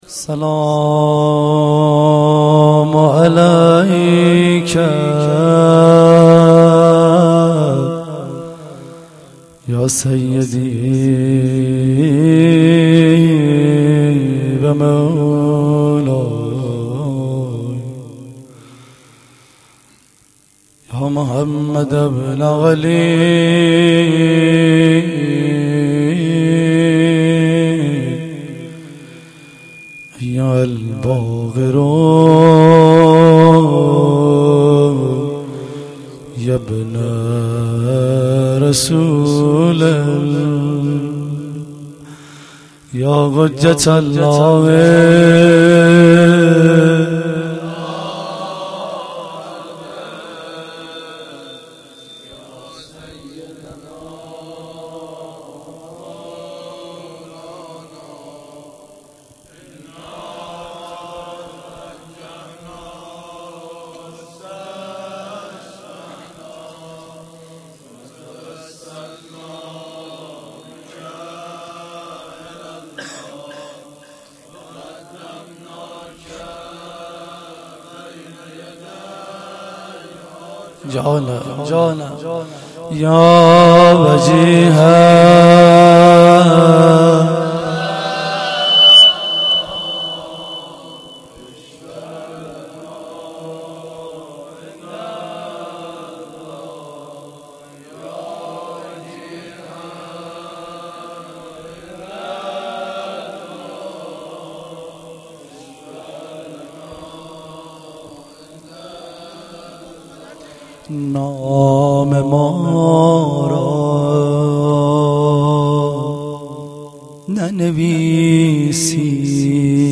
روضه
roze-Rozatol-Abbas.shahadat-emam-Bagher.mp3